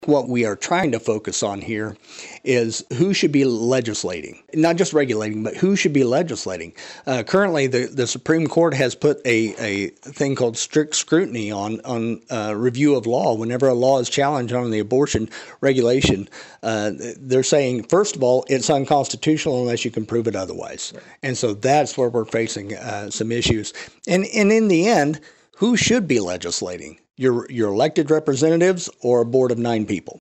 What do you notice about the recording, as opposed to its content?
The Emporia Area Chamber of Commerce Government Matters Committee and League of Women Voters’ final legislative dialogue of the year drew a significant crowd to the Trusler Business Center Tuesday night.